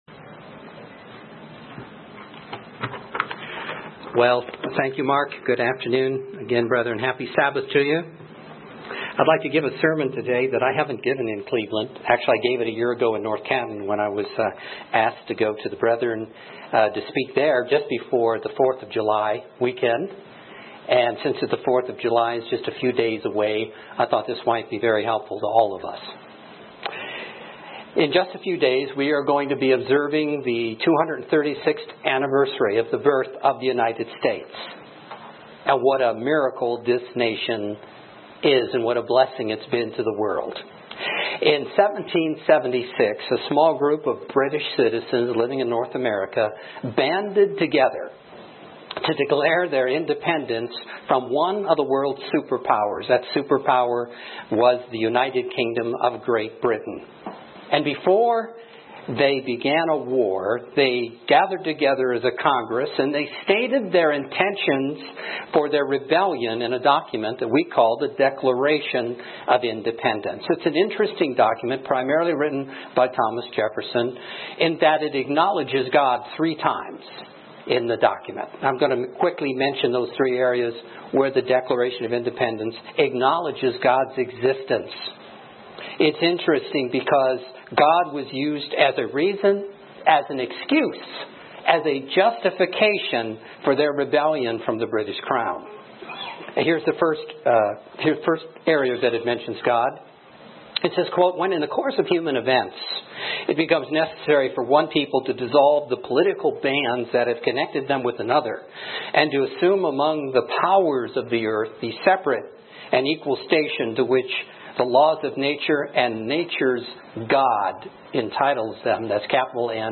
This sermon examines the history and lives of our Founding Fathers and their reliance on Our Great God for success. Five points are given for Patriots to God's Kingdom, to examine our commitment and dedication to the cause.